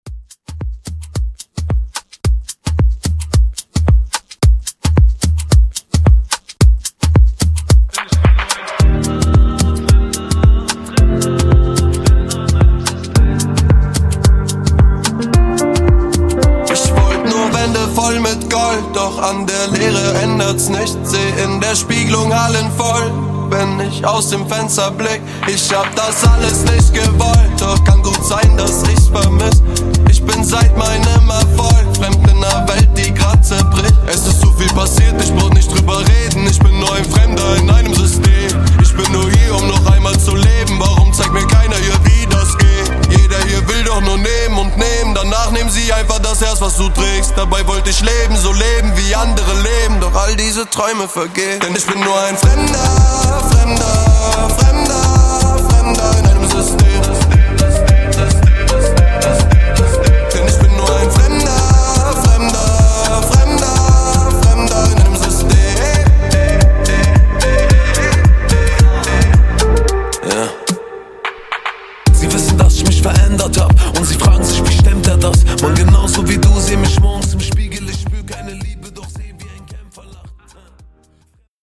Genre: COUNTRY
Clean BPM: 156 Time